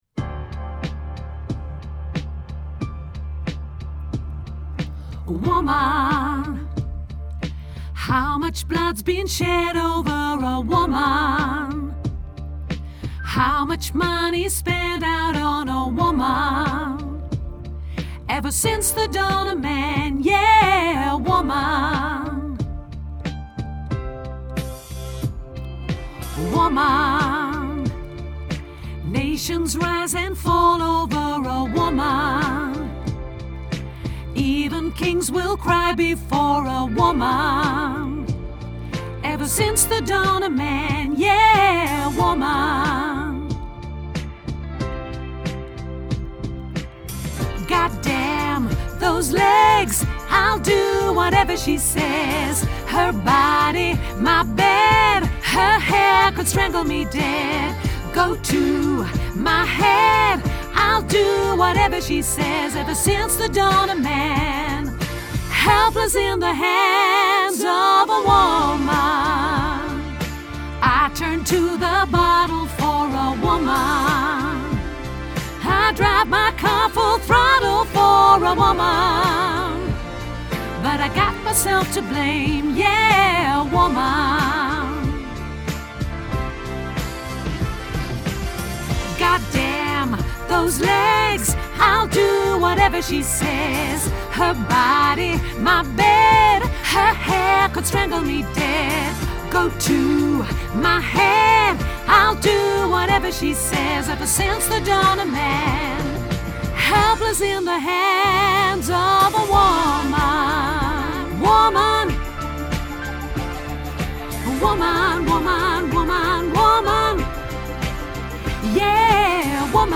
alt
Woman Alt Grote Koor Mp 3